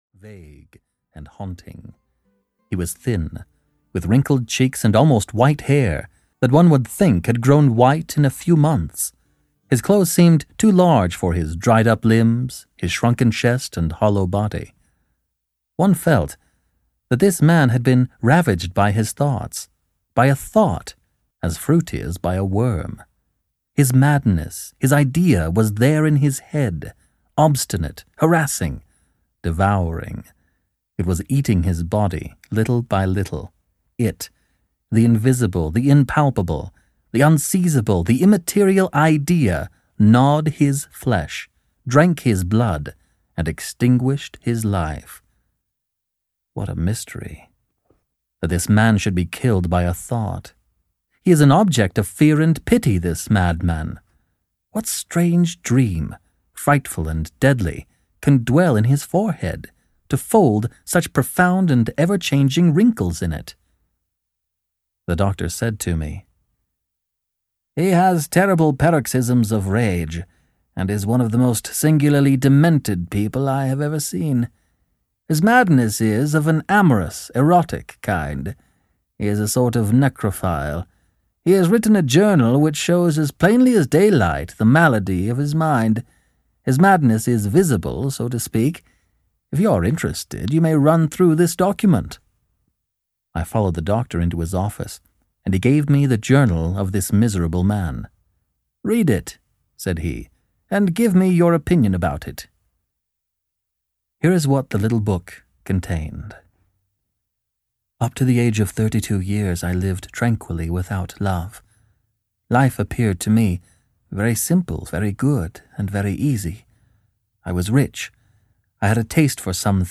Ukázka z knihy
He masterfully plays with a wide array of voices and accents and has since then produced over 500 audiobooks.